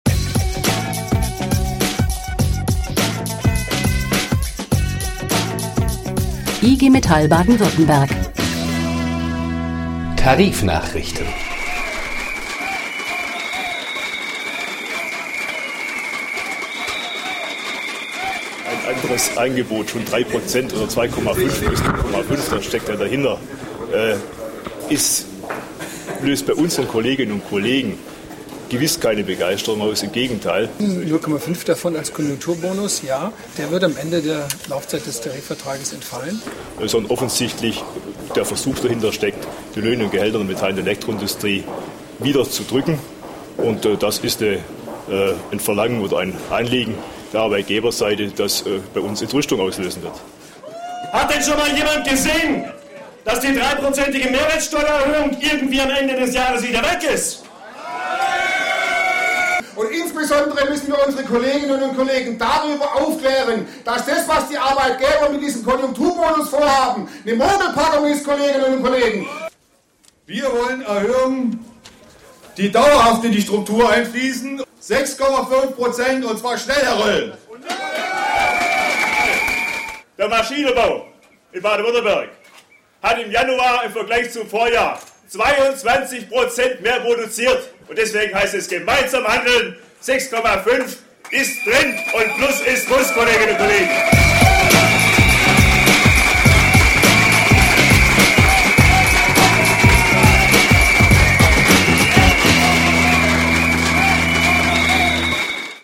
Stimmen zur zweiten Runde der Tarifverhandlungen, bei der die Arbeitgeber ein Angebot mit 2,5 % mehr Einkommen sowie einen vorübergehenden "Konjunkturbonus" von 0,5 % vorlegten.